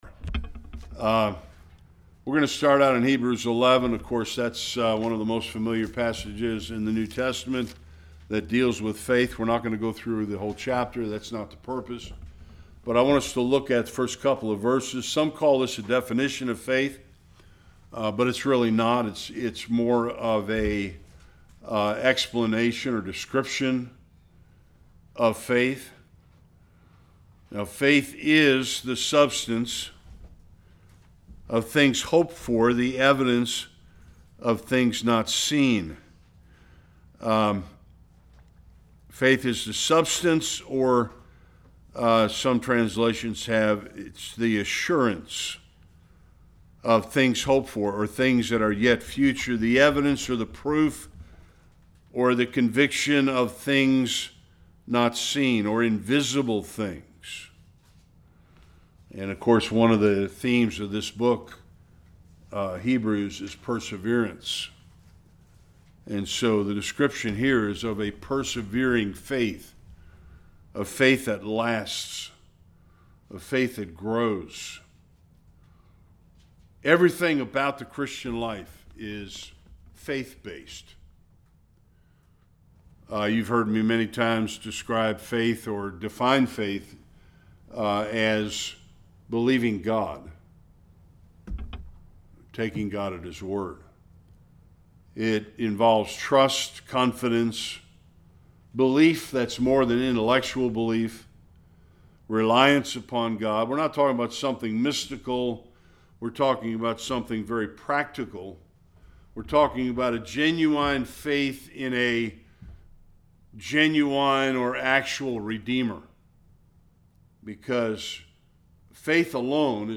Various Passages Service Type: Bible Study Faith is not only essential for salvation but is necessary in all aspects of the Christian life.